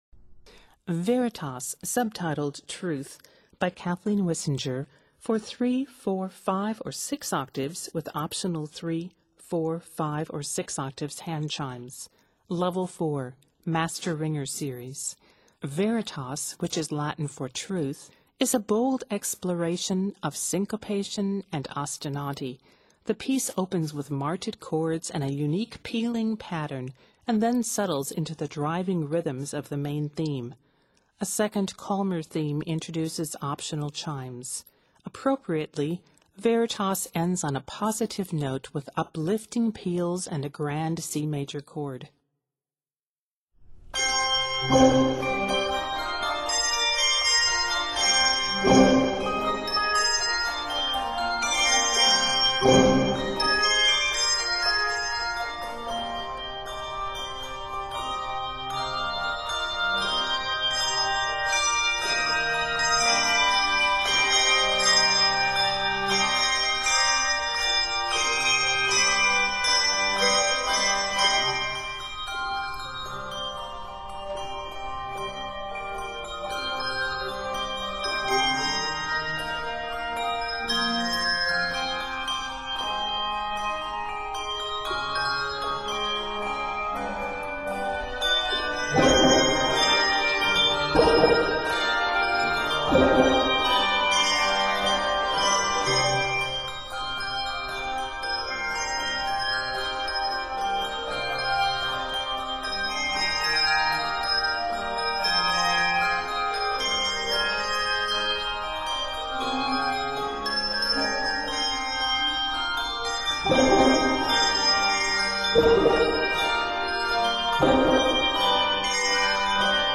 A bold exploration of syncopation and ostinati
A second, calmer theme introduces optional chimes.
It is written in Eb Major and C Major and is 111 measures.